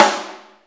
Subtle Reverb Snare Single Hit G# Key 413.wav
Royality free snare sound tuned to the G# note. Loudest frequency: 1783Hz
subtle-reverb-snare-single-hit-g-sharp-key-413-G4Y.mp3